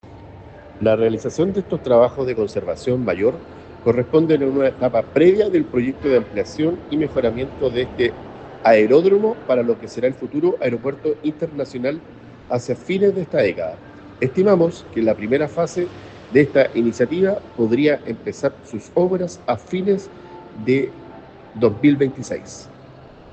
Dennys-Mendoza-Seremi-de-Obras-Publicas.mp3